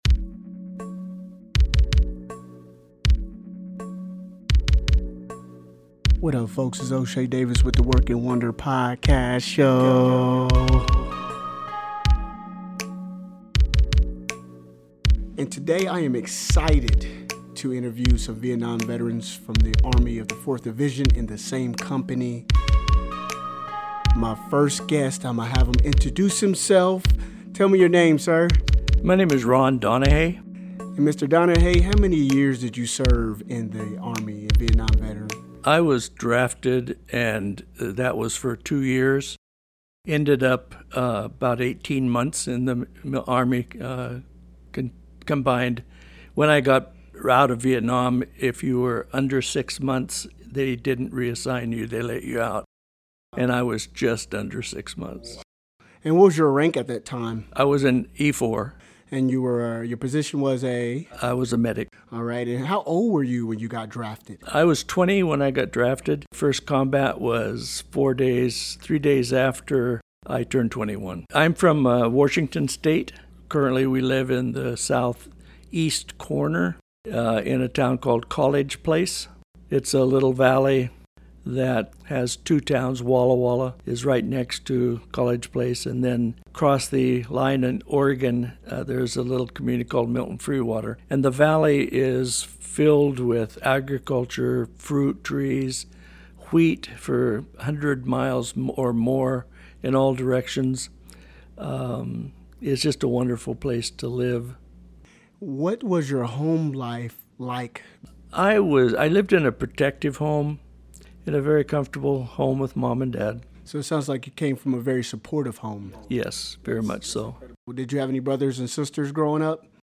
The recording had to be cut short due to a coughing spell.